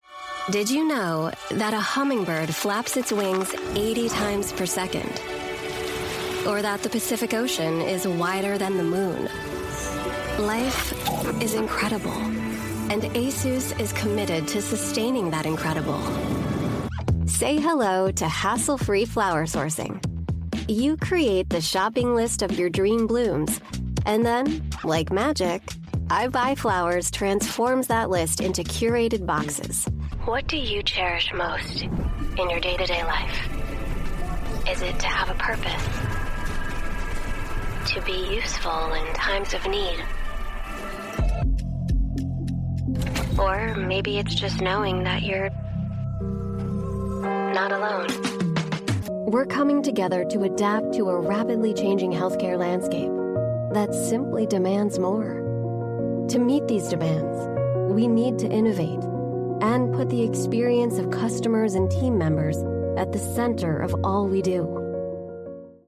English (American)
Commercial, Young, Warm, Natural, Friendly
Corporate